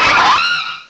prinplup.aif